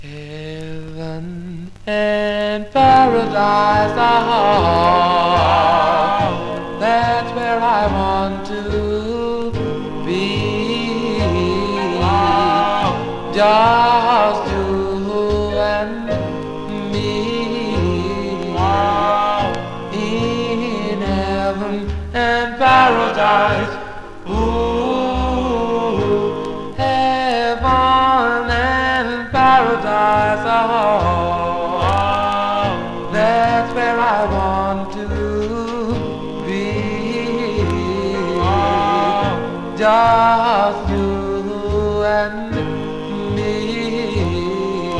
Rhythm & Blues, Doo Wop　USA　12inchレコード　33rpm　Mono